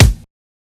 Havoc Kick 30.wav